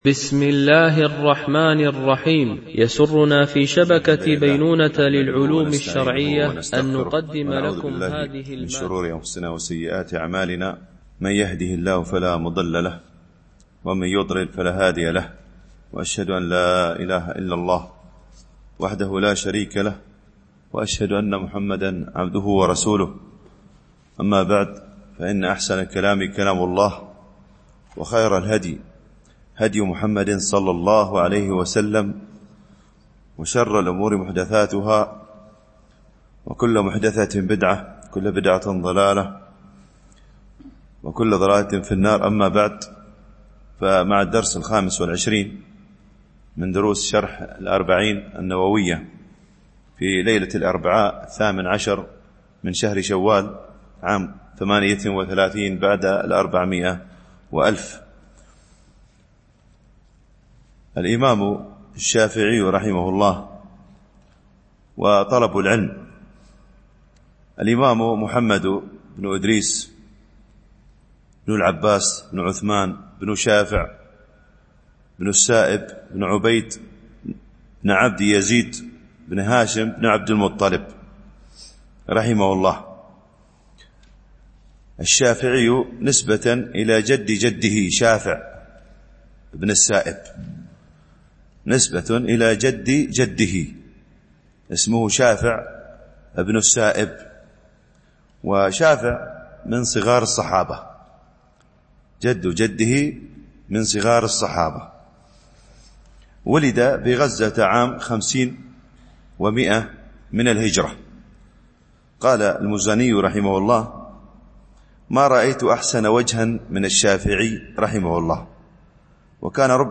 شرح الأربعين النووية ـ الدرس 24 (الحديث 11 - 12)
التنسيق: MP3 Mono 22kHz 32Kbps (CBR)